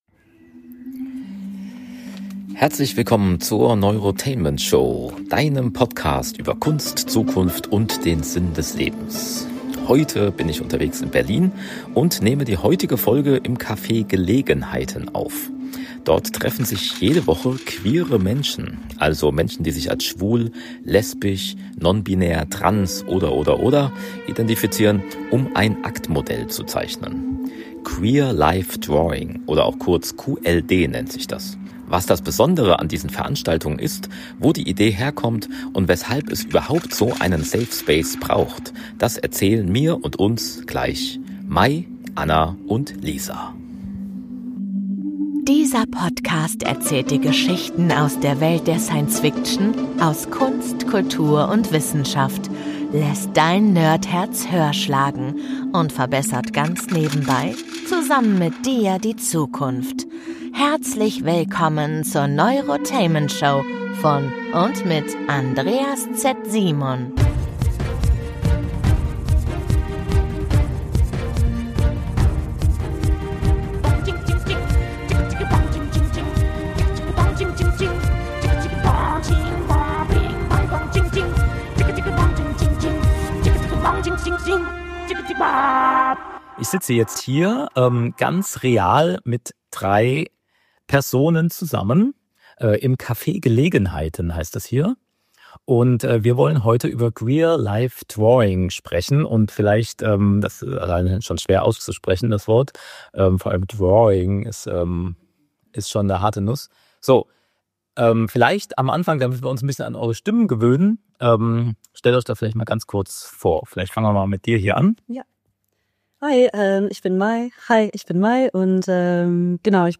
Heute bin ich unterwegs in Berlin und nehme diese Folge der Neurotainment Show im Cafe "Gelegenheiten" auf.